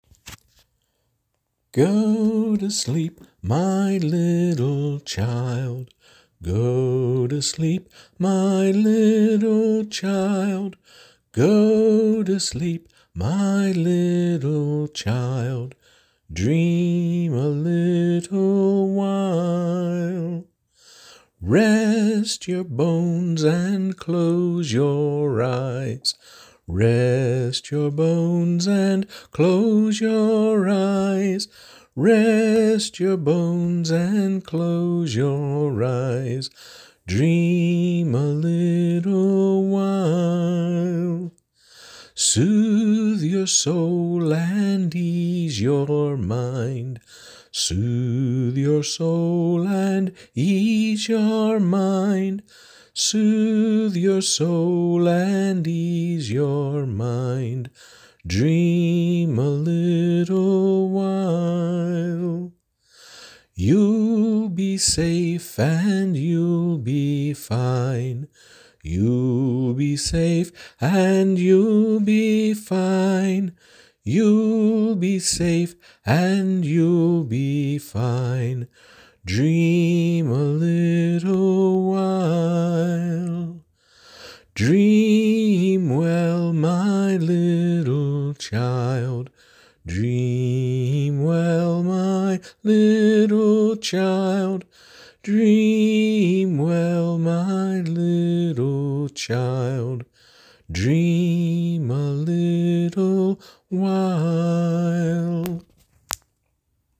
Despite all the miscues of this week — the poor communications, the questionable decisions, the visit to urgent care, bailing out of LibertyCon because I’m not in the right “head space” — I did manage to do something nice: I wrote a lullaby.
As lullabies go, it’s nothing special — just soft, repeated phrases with a tie-in at the end. It’s called “Dream a Little While,” and the first verse goes like this:
And here’s yours truly doing a quick-and-dirty recording on his phone: